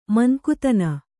♪ mankutana